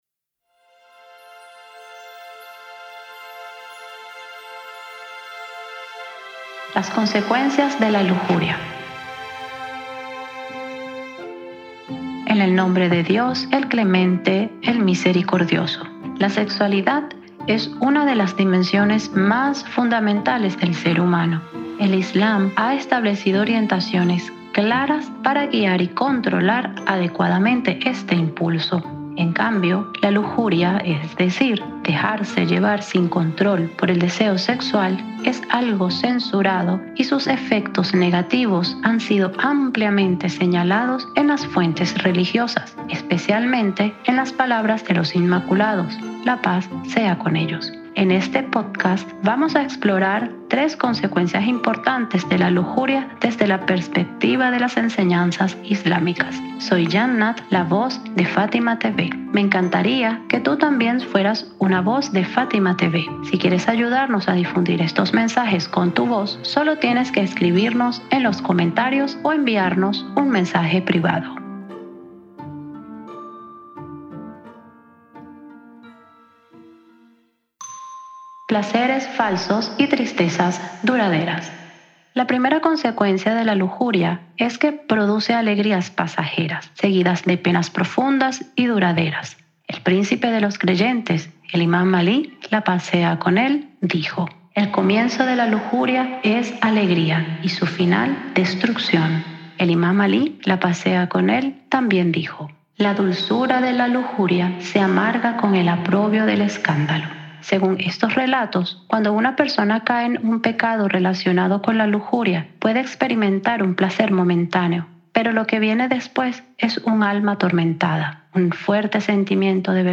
🎙 Locutora: